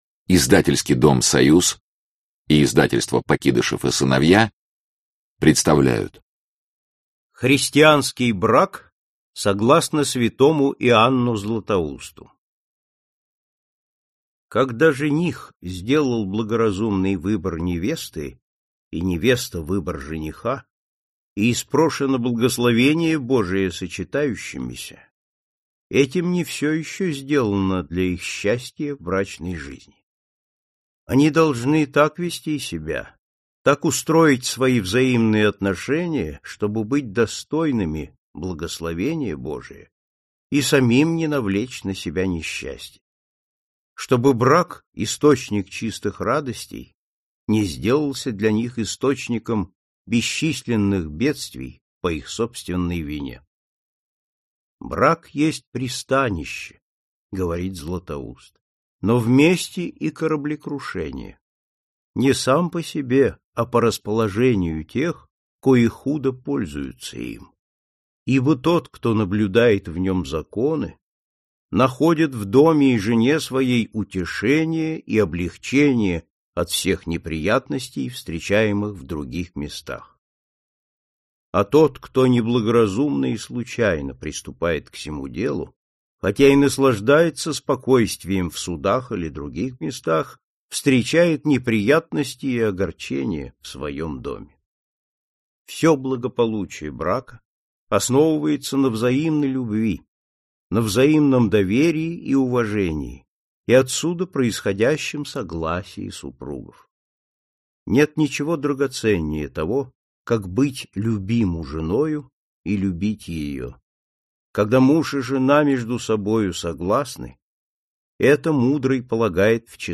Аудиокнига Христианский брак | Библиотека аудиокниг